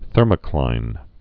(thûrmə-klīn)